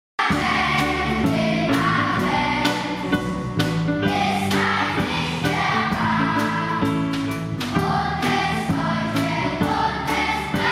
Die Schülerinnen und Schüler der Jahrgangsstufe fünf sorgen mit ihrem Gesang für ganz besondere Momente im Advent